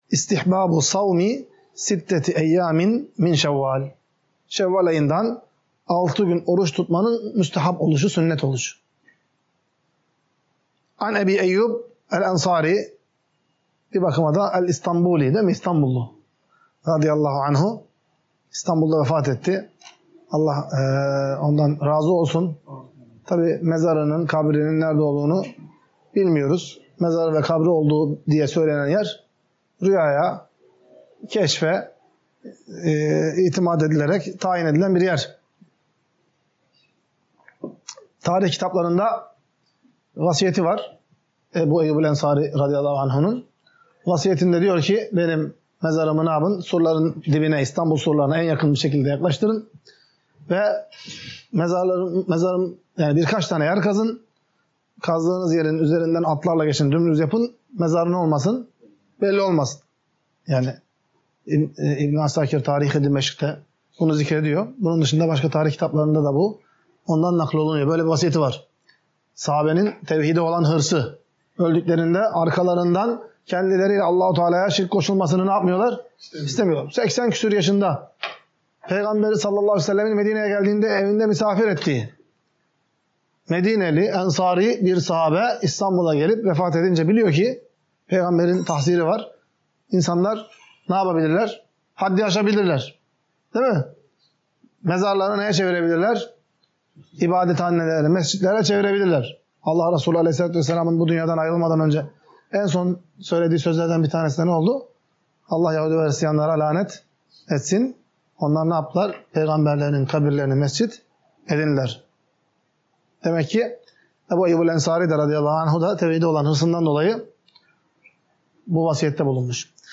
Ders - 49.